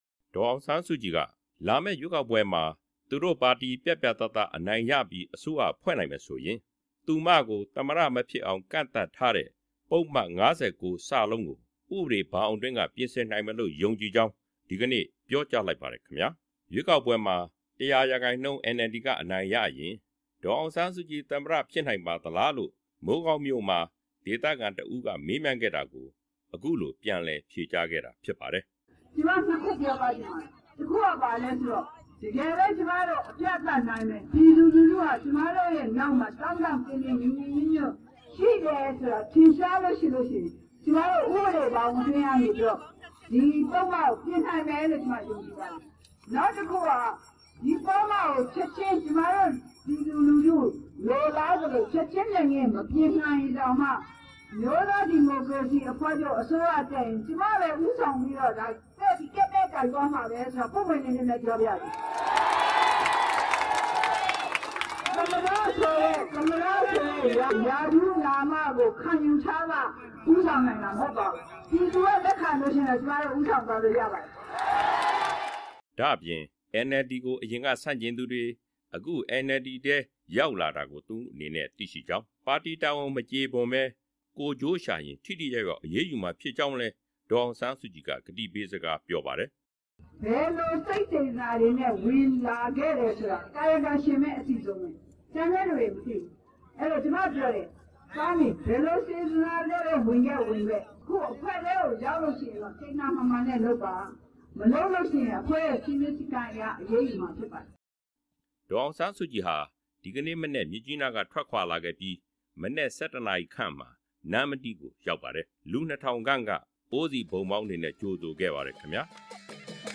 ကချင်ပြည်နယ် မိုးကောင်းမြို့၊ နတ်ကြီးကုန်းရပ်ကွက် အားကစားကွင်းမှာ ဒီကနေ့မနက် ၁၂ နာရီခန့်က ကျင်းပတဲ့ မဲဆွယ်လူထုဟောပြောပွဲမှာ အခုလို ပြောကြားလိုက်တာပါ။